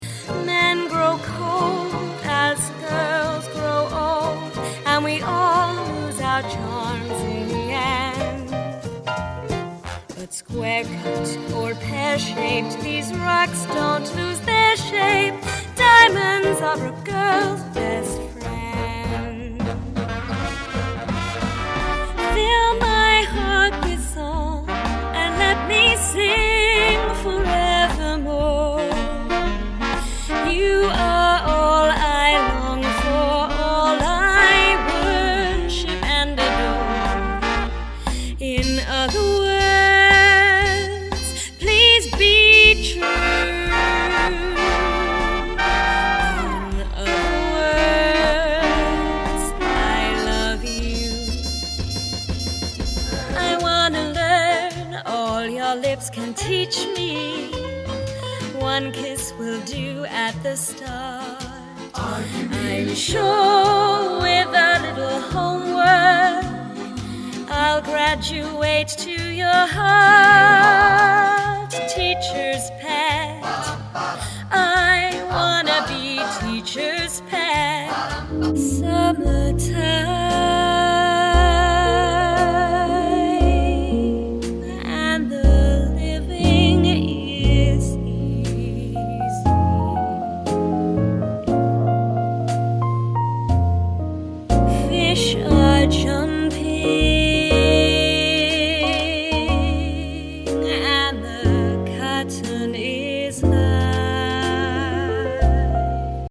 Vintage Jazz and Swing Singer
pleasing blend of upbeat swing numbers and gorgeous ballads